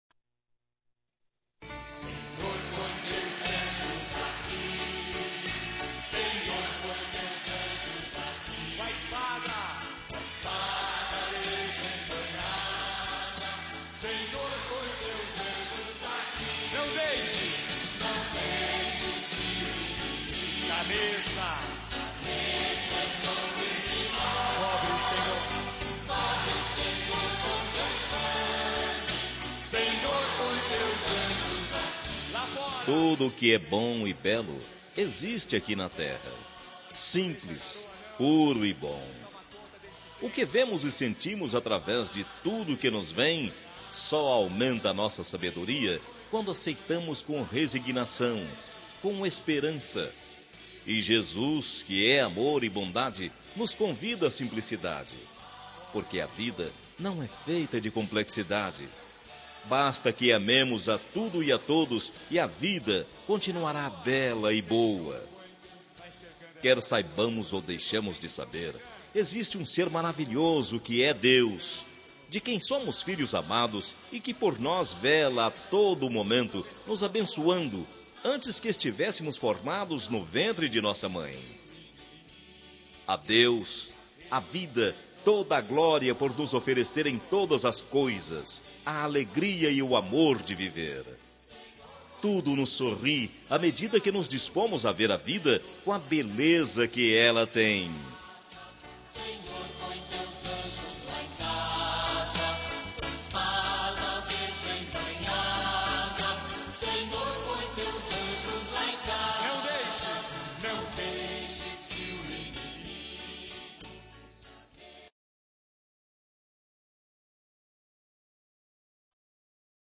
Telemensagem de Otimismo – Voz Masculina – Cód: 4990-5 – Religiosa